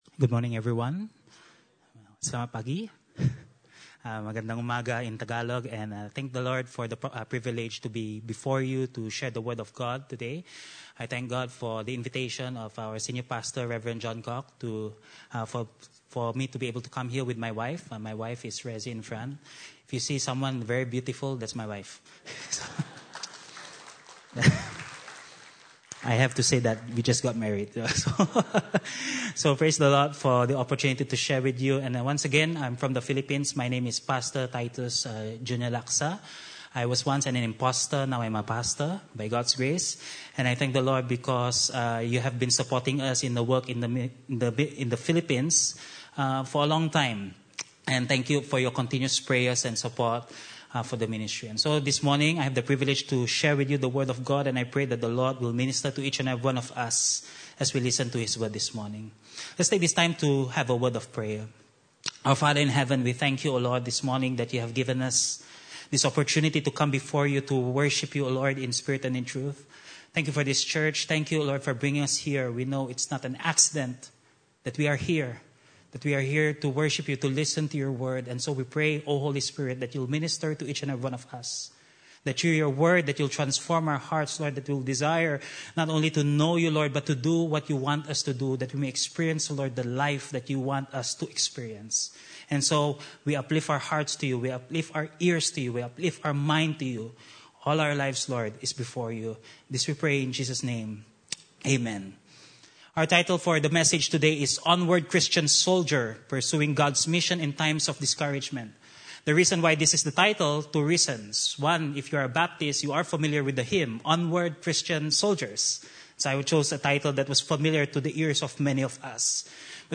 Acts 26:12-18 Service Type: Sunday Service